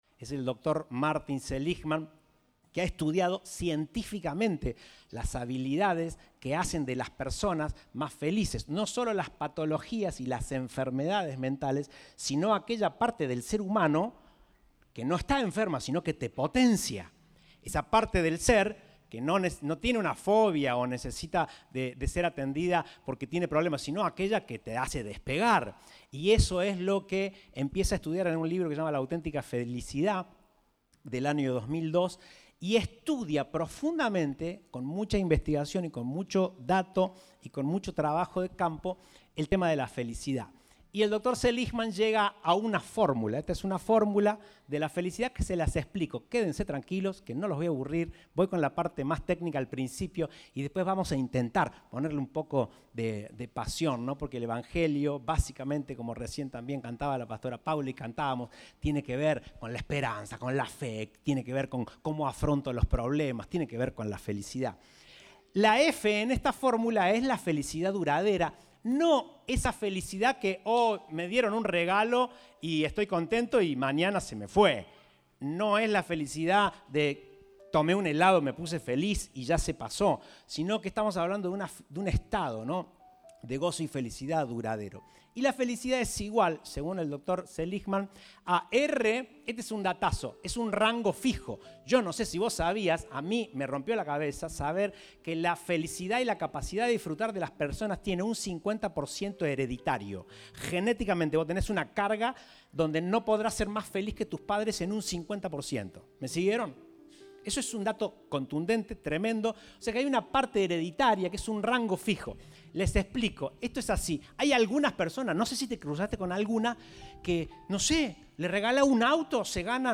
Compartimos el mensaje del Domingo 02 de Julio de 2023